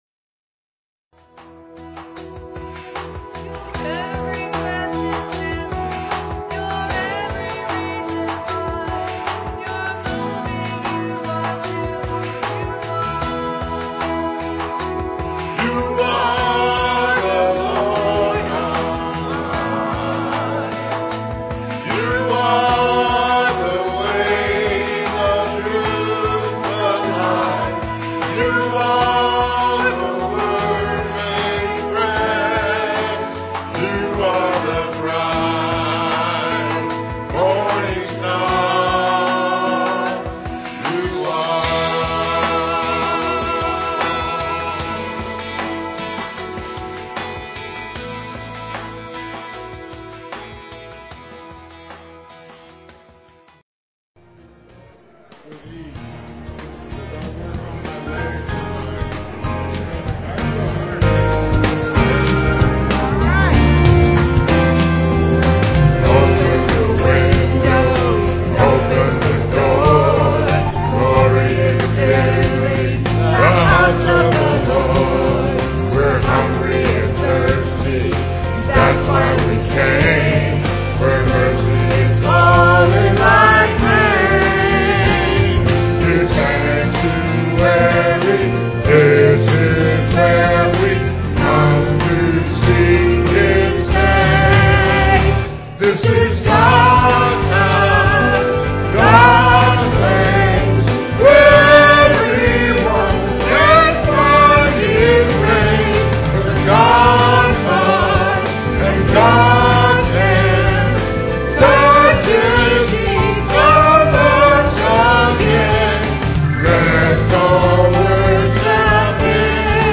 piano
digital piano.